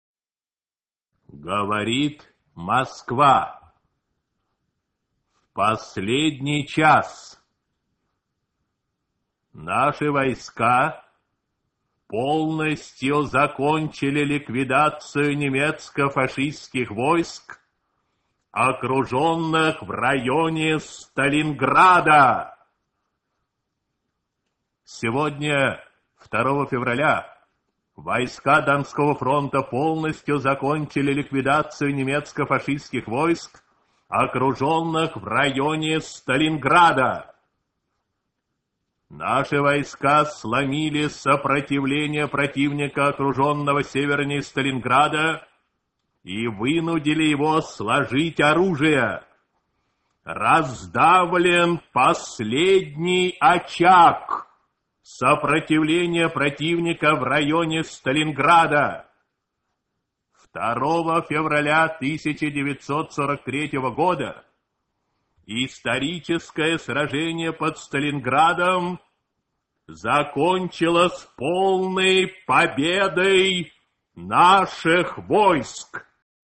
На этой странице собраны архивные записи голоса Юрия Левитана — символа эпохи.
Качество звука восстановлено, чтобы передать мощь и тембр легендарного диктора.